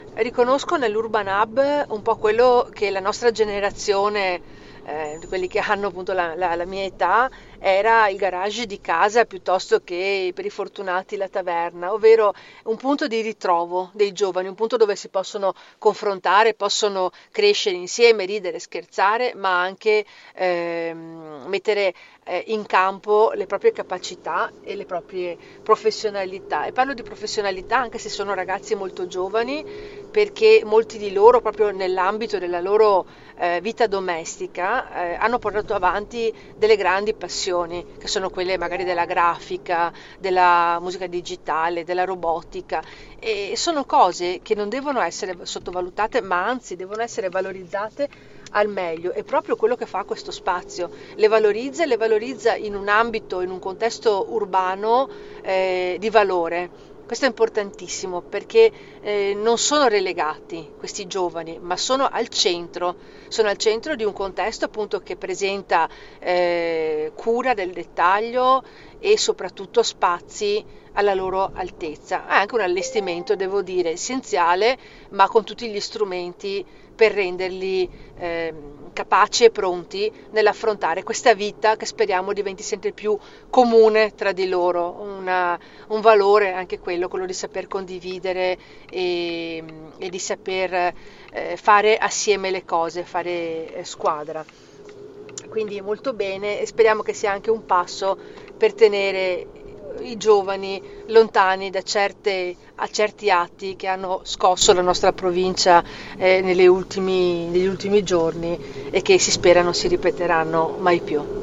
BELLUNO C’era anche la consigliera regionale Silvia Cestaro, all’inaugurazione delle attività dell’Urban Hub organizzato dal Centro Consorzi di Sedico al piano terra di uno dei locali che si affacciano nella nuova piazza del capoluogo, quella intitolata ad Angelina Zampieri.
SILVIA CESTARO, CONSIGLIERE REGIONALE